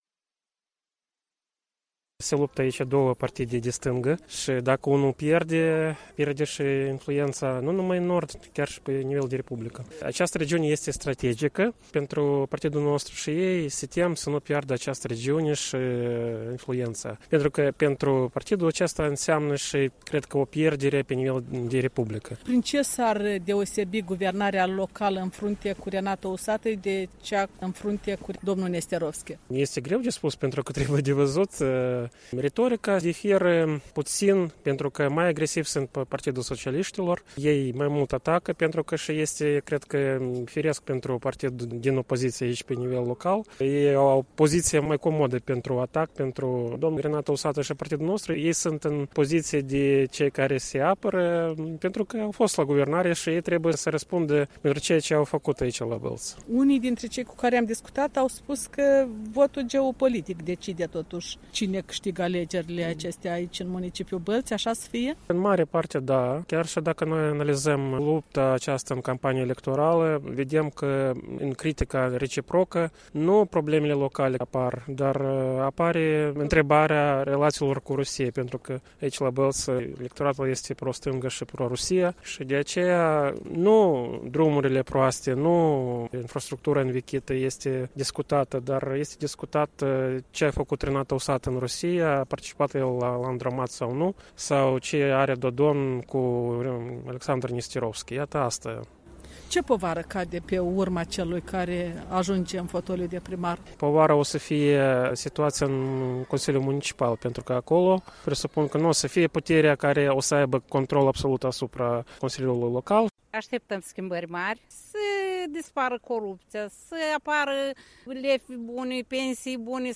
Bălți: opinii ale alegătorilor